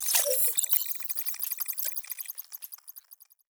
Digital Bling Alert 2.wav